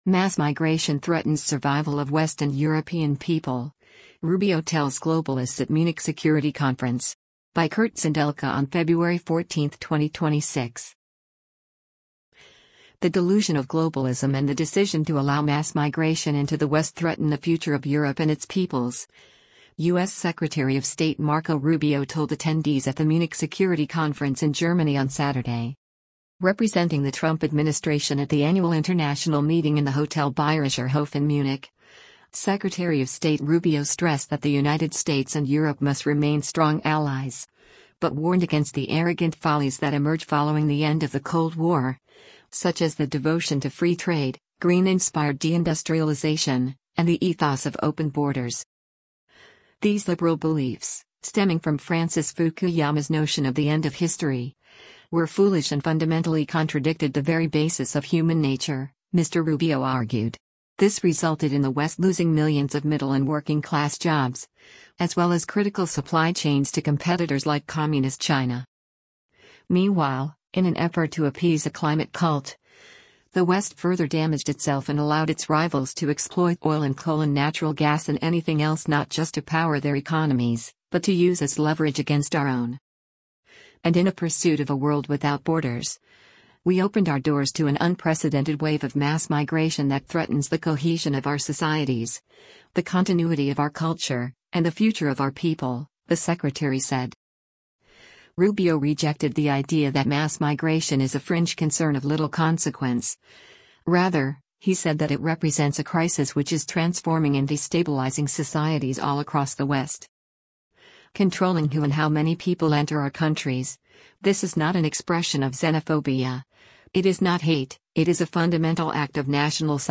The “delusion” of globalism and the decision to allow mass migration into the West threaten the future of Europe and its peoples, U.S. Secretary of State Marco Rubio told attendees at the Munich Security Conference in Germany on Saturday.